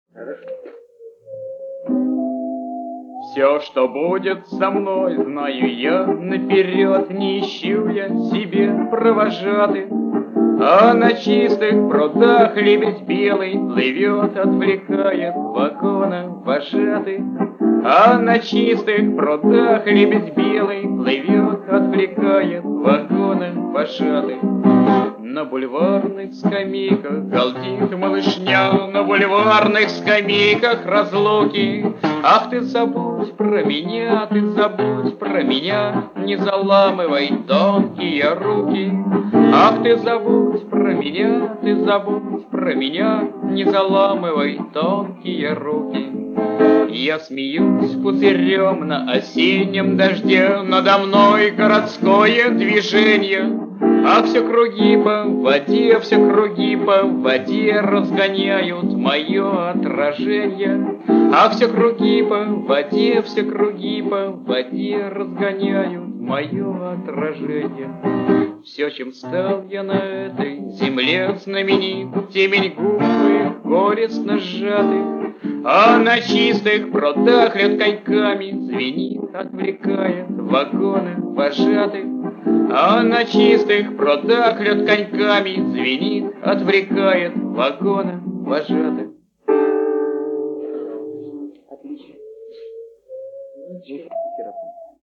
Запись дома у Евгения Клячкина, 1965 г